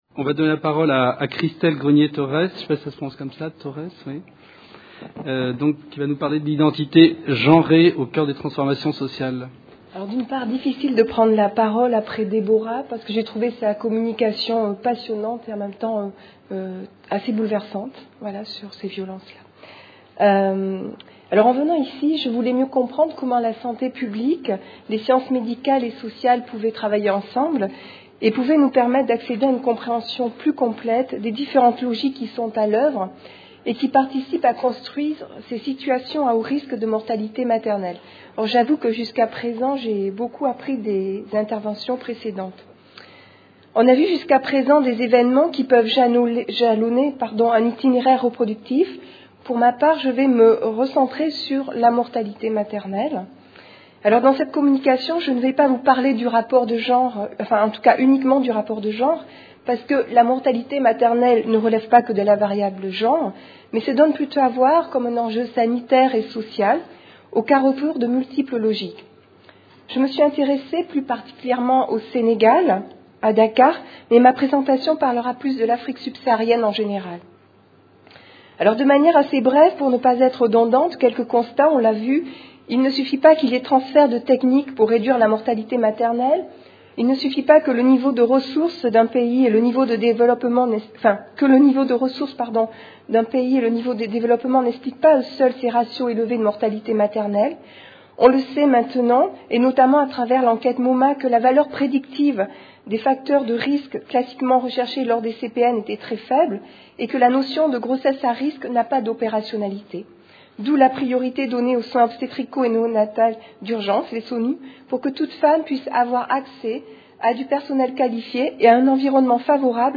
Conférence enregistrée dans le cadre du Colloque International Interdisciplinaire : Droit et Santé en Afrique. Réduction de la mortalité maternelle en Afrique Sub-saharienne, mieux comprendre pour mieux agir. 1ère Session : Fécondité, sexualité et planning familial.